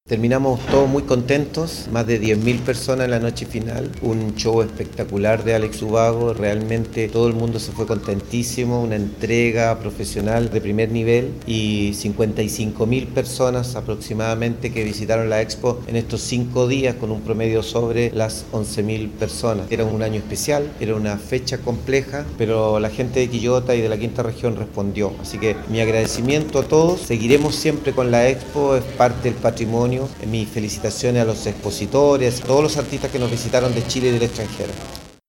El alcalde de Quillota, doctor Luis Mella, manifestó que “terminamos todos muy contentos. Más de 10 mil personas la noche final. Un show espectacular de Alex Ubago. Realmente todo el mundo se fue contentísimo, una entrega profesional de primer nivel. Y 55 mil personas aproximadamente que visitaron la Expo en estos cinco días, con un promedio sobre las 11 mil personas. Era un año especial, era una fecha compleja, pero la gente de Quillota y de la Quinta Región respondió, así que mi agradecimiento a todos. Seguiremos siempre con la Expo, es parte del patrimonio y mis felicitaciones a los expositores y a todos los artistas que nos visitaron de Chile y el extranjero”.
04-ALCALDE-LUIS-MELLA-Balance.mp3